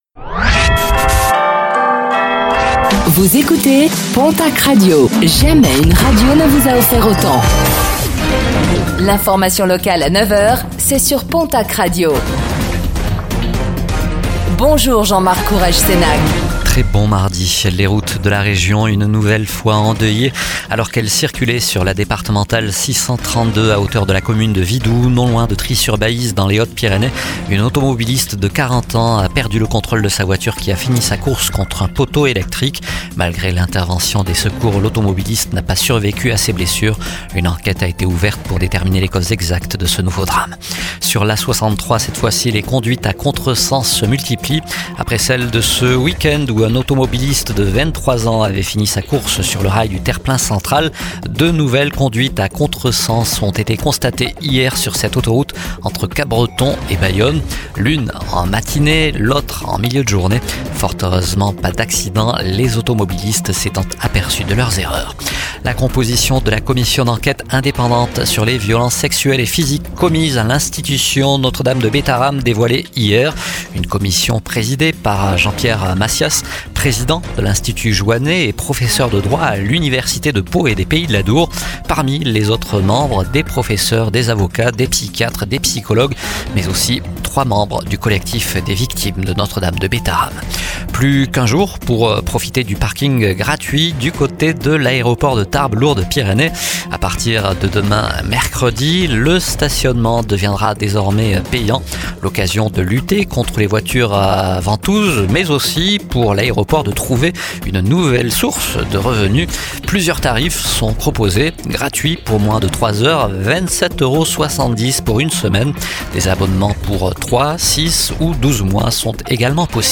Réécoutez le flash d'information locale de ce mardi 22 avril 2025